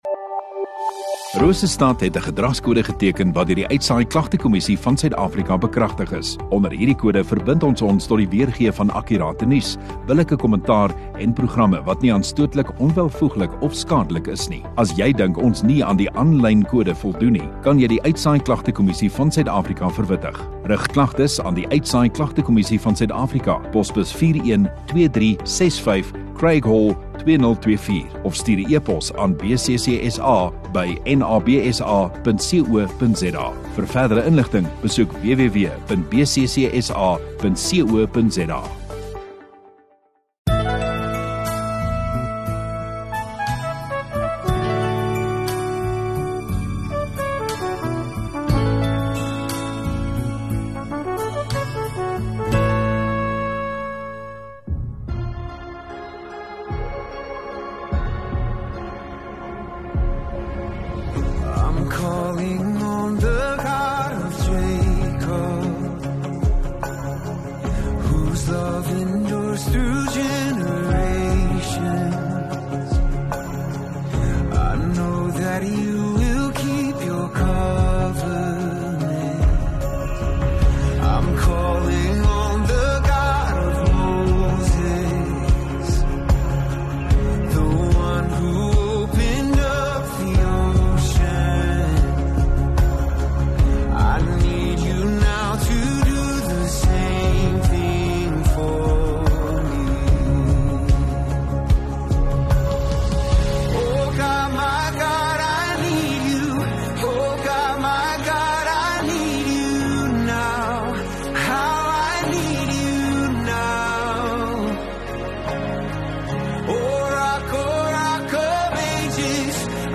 View Promo Continue Install Rosestad Godsdiens 25 Jan Saterdag Oggenddiens